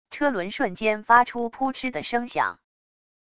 车轮瞬间发出噗嗤的声响wav音频